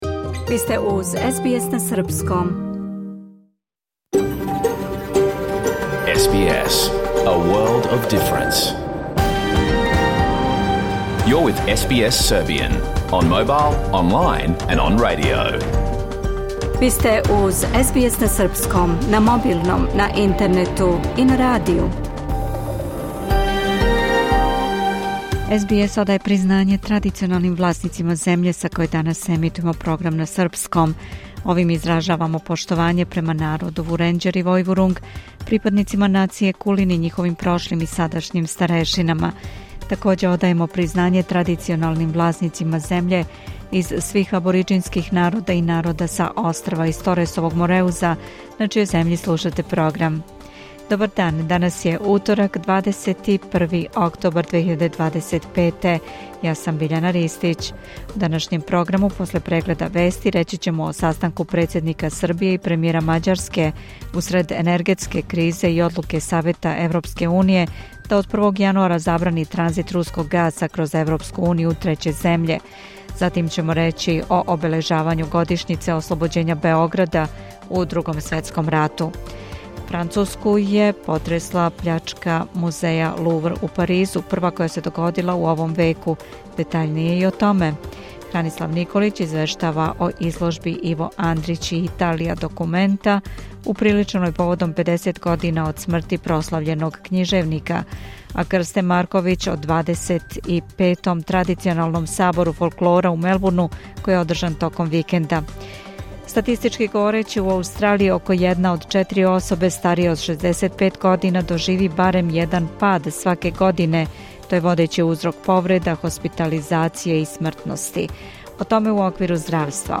Програм емитован уживо 21. октобра 2025. године